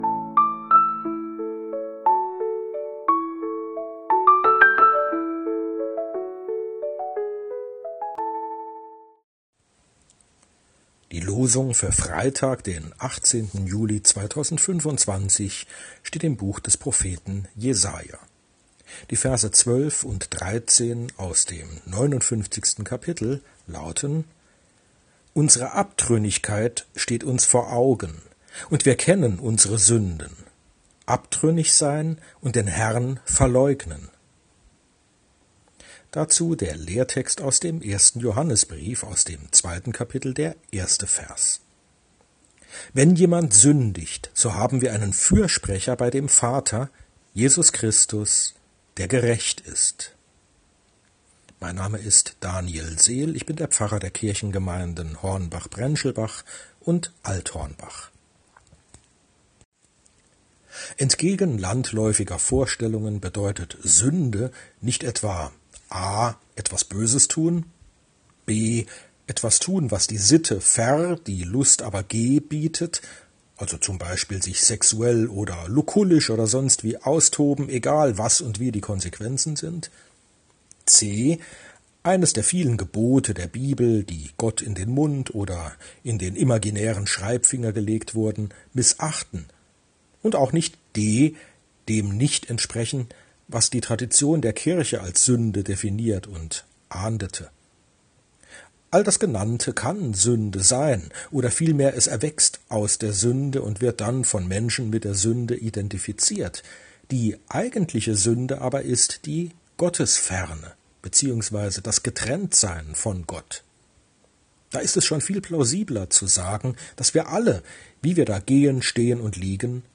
Losungsandacht für Freitag, 18.07.2025